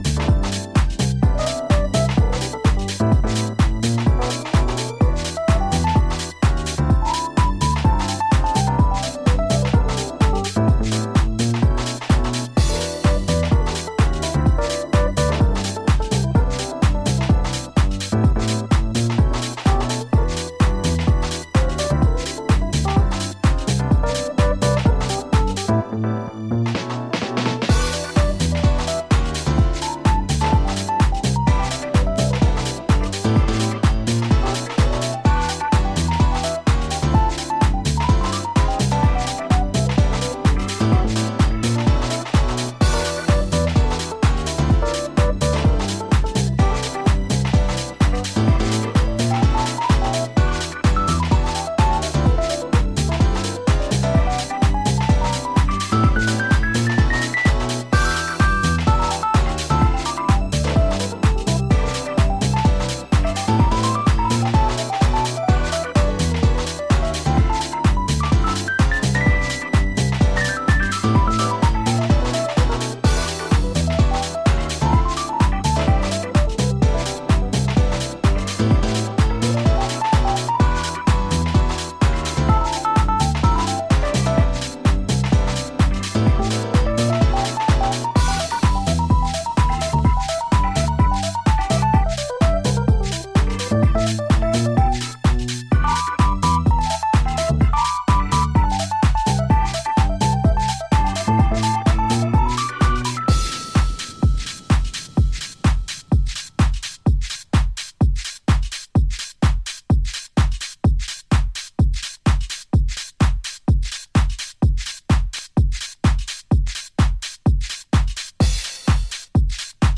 deep house